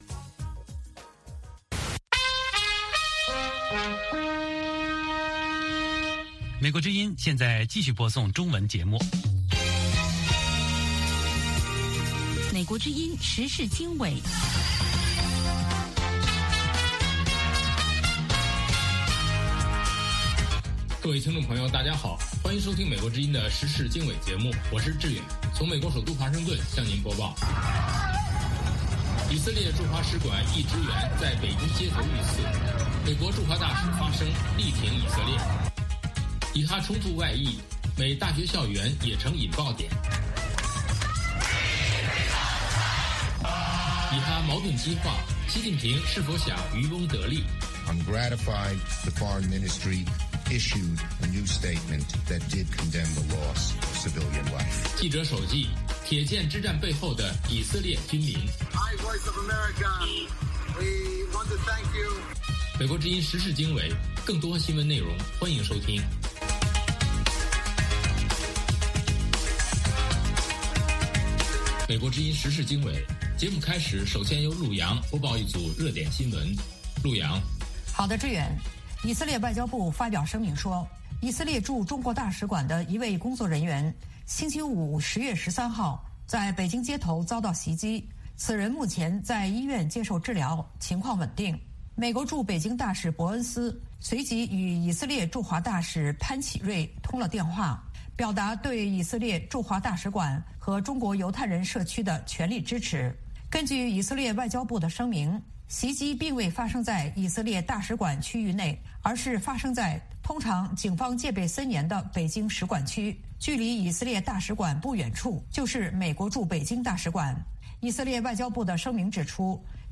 美国之音英语教学节目。